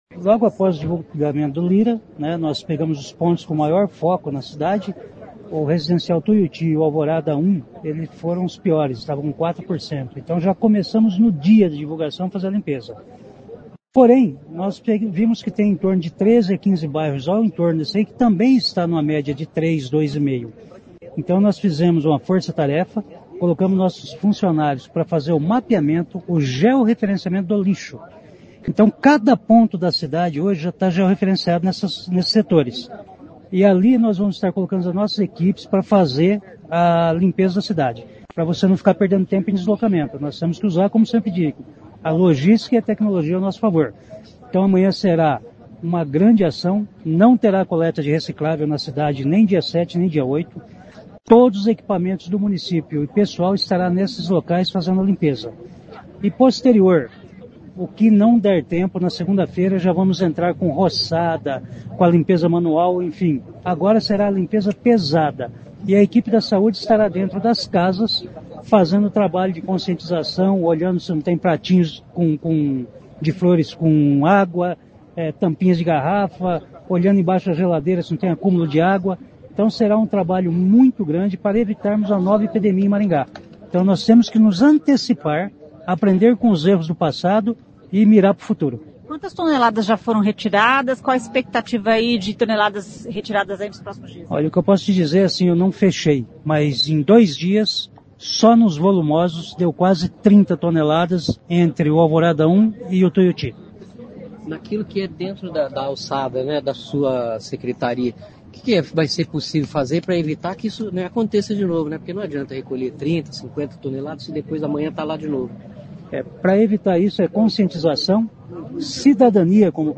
O secretário de Infraestrutura, Limpeza Urbana e Defesa Civil de Maringá Vagner Mussio disse que as equipes do município mapearam a cidade por georreferenciamento e sairão nessa sexta-feira recolhendo o lixo nestes pontos.